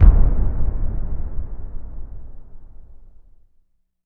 LC IMP SLAM 7C.WAV